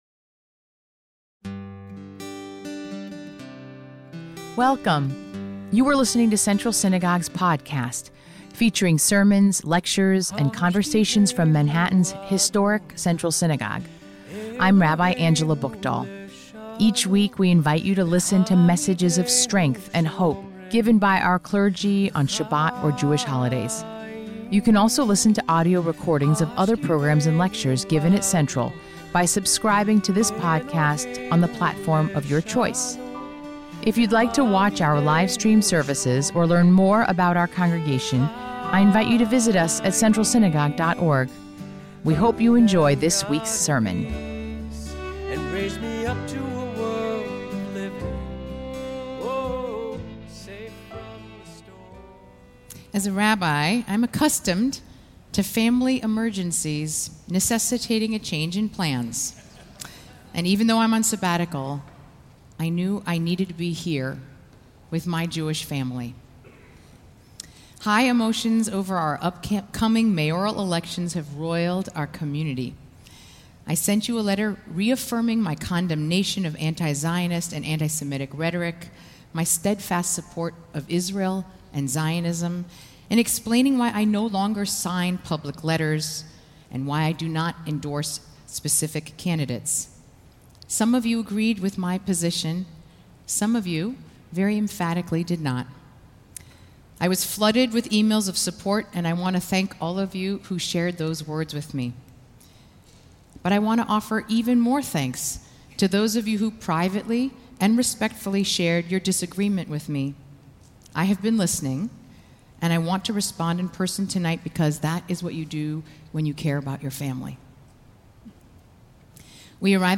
Sermon
Kol Nidre 5784